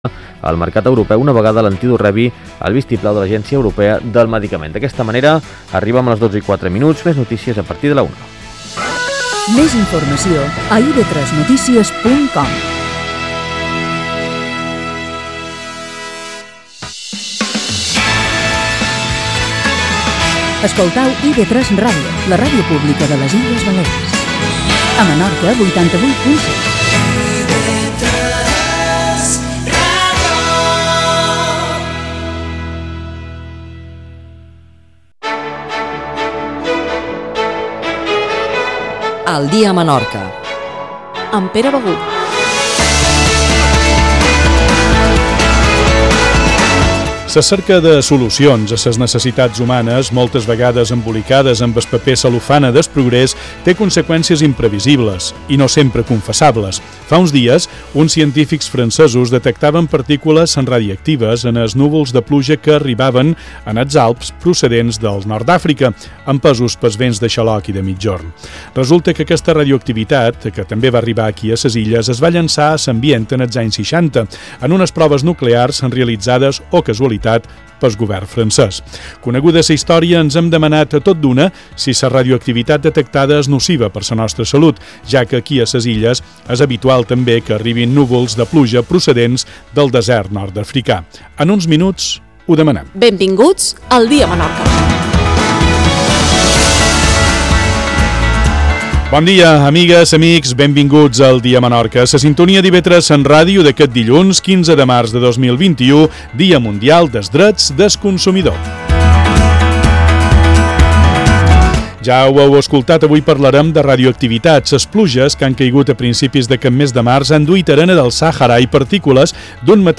Entrevista
15 de març de 2021 Enllaç al programa complet (la entrevista va del minut 11:24 al 21:20) .